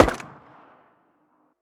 usp1-distant.ogg